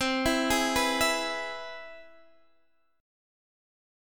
C+M7 chord